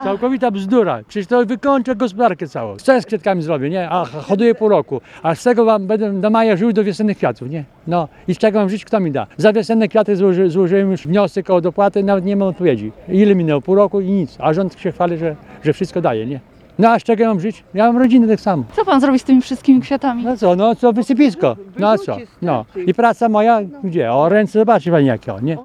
– pytał retorycznie spotkany przez reporterki Radia 5 na ełckiej targowicy jeden z przedsiębiorców sprzedających chryzantemy.